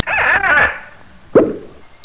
KISSPOP.mp3